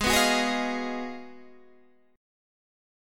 G#m13 Chord
Listen to G#m13 strummed